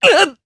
Zafir-Vox_Damage_jp_03.wav